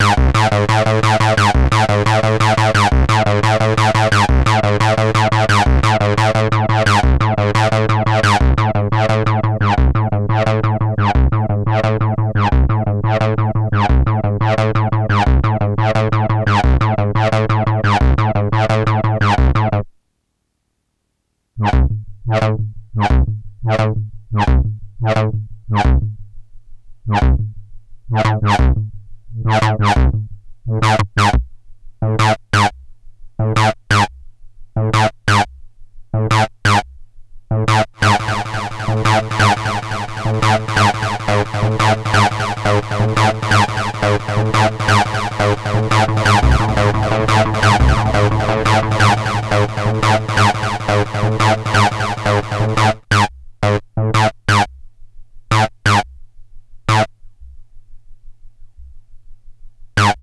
d'abord, une loop TB dry puis passée dans le filterbox, ensuite pour la deuxième partie, mod slicer avec rajout de delay, ça le fait bien.